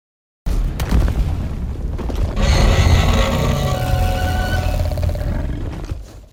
alatreon-roar-small.mp3